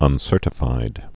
(ŭn-sûrtə-fīd)